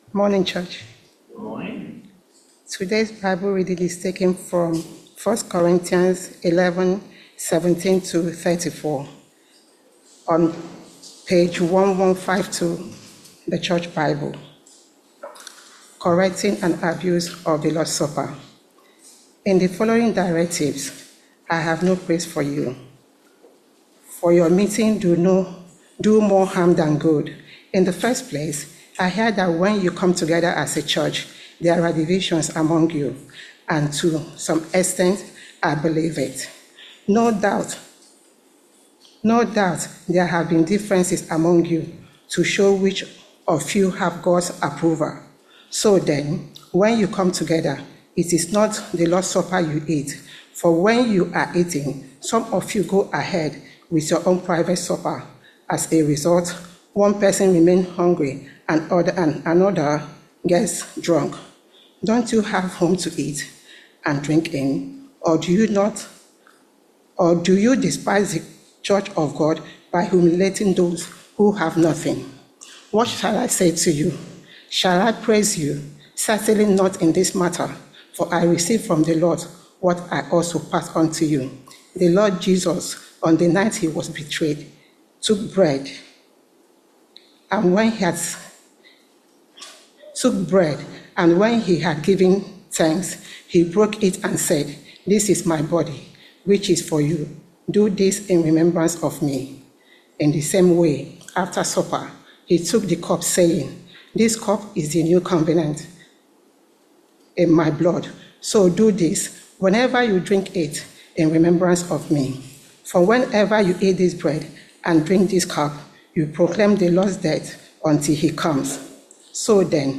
I Corinthians 11vv17-34 Service Type: Sunday Morning Service Topics: The Lord's Supper « Mark 14vv12-26 Ruth 2 »